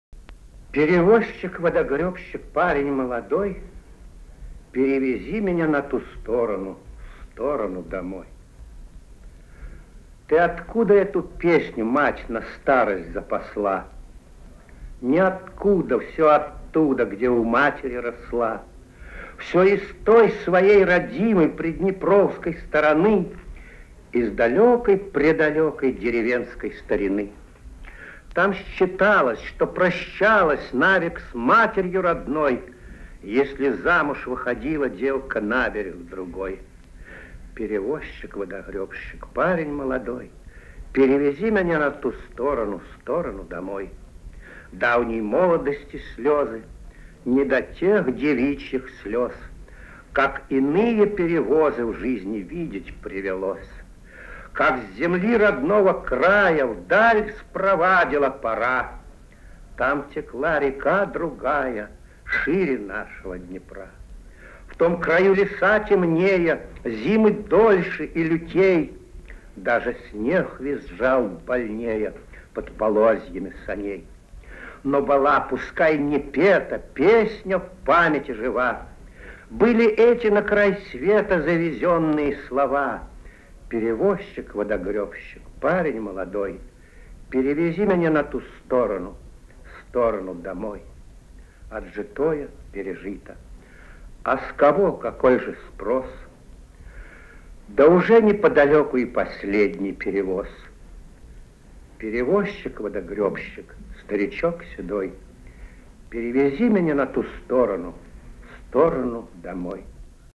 Александр Твардовский - Ты откуда эту песню... (Памяти матери) Читает автор.
a.t.-tvardovskiy---tyi-otkuda-etu-pesnyu.mp3